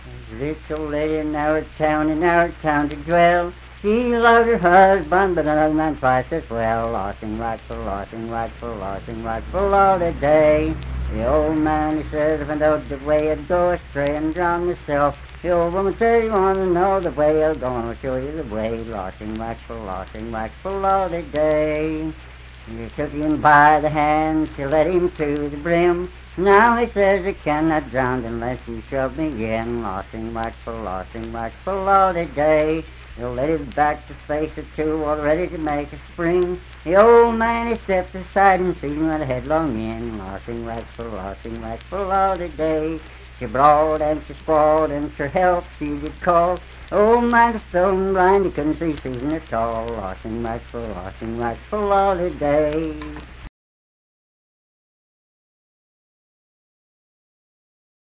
Unaccompanied vocal music performance
Verse-refrain 5(6w/R).
Voice (sung)
Clay County (W. Va.)